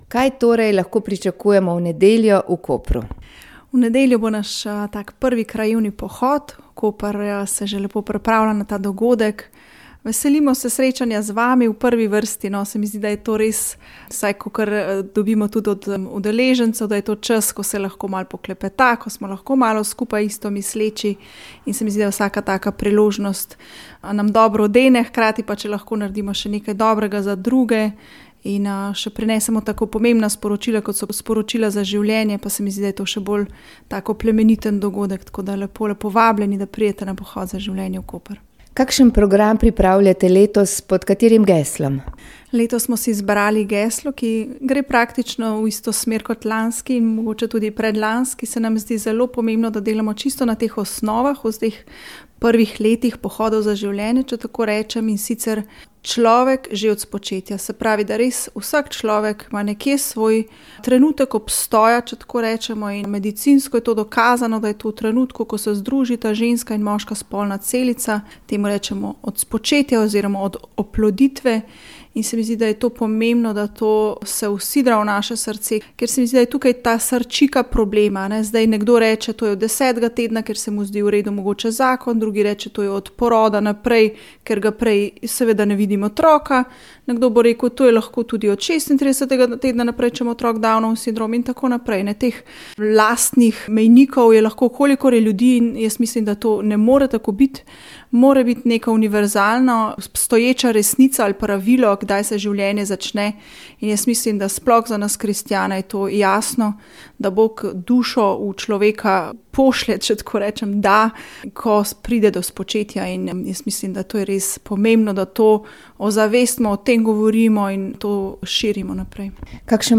V oddaji o ljudski glasbi ste poslušali posnetek koncerta Zajuckaj in zapoj, ki se je odvil 18. oktobra v Atriju ZRC SAZU.